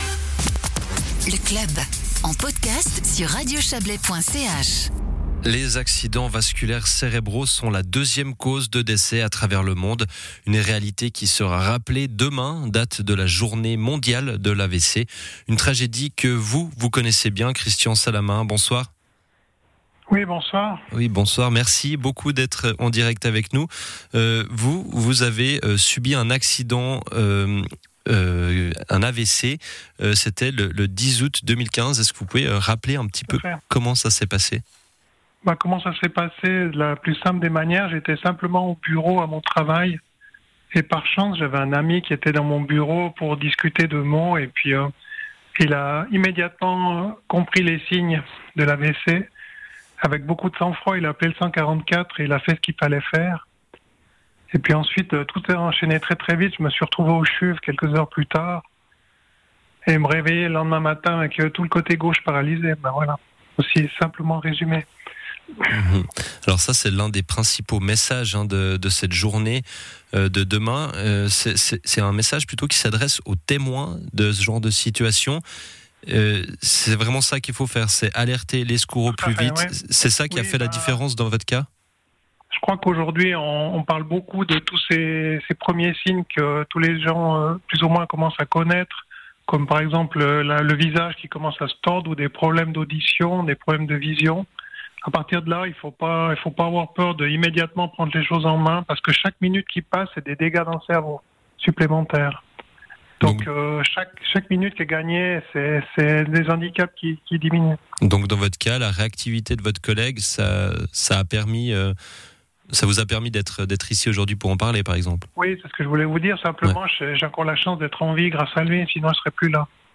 Journée mondiale de l'AVC: Interview